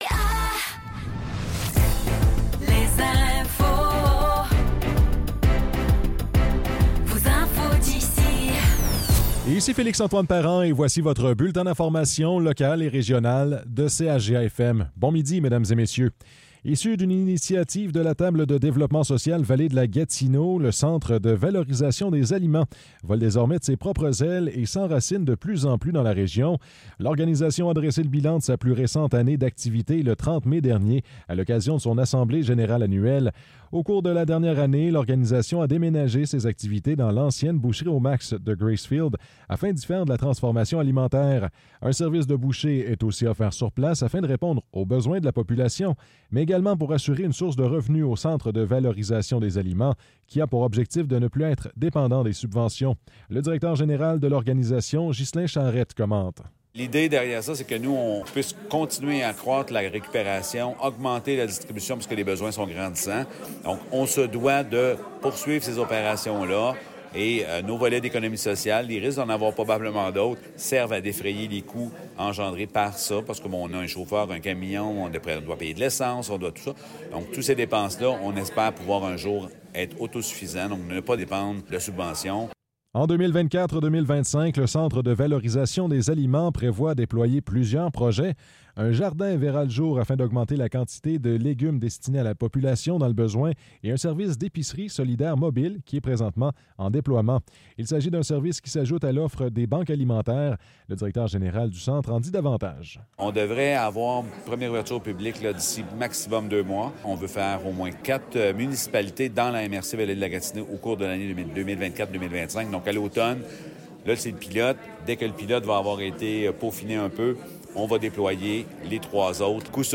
Nouvelles locales - 4 juin 2024 - 12 h